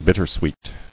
[bit·ter·sweet]